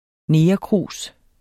Udtale [ ˈneːjʌˌkʁuˀs ]